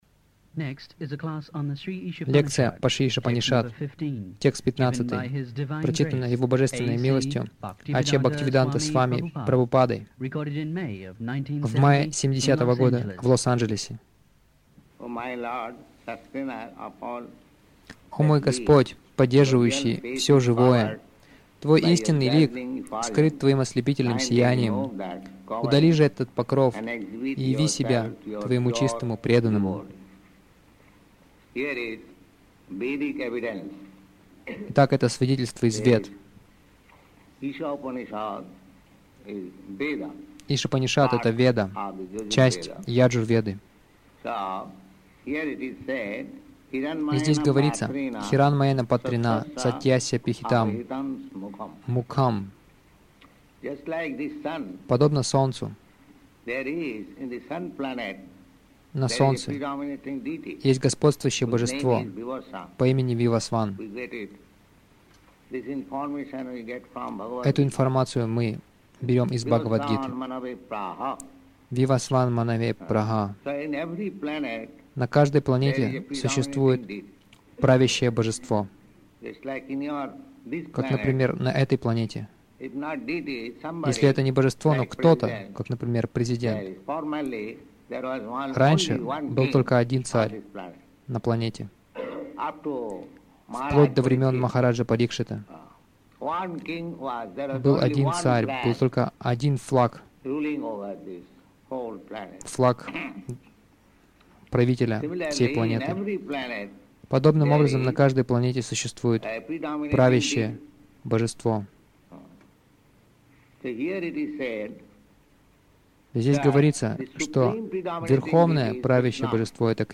Слушать лекции по Шри Ишопанишад